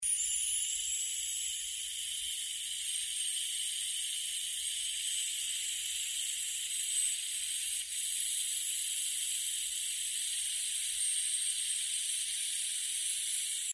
Whistle_Sound.mp3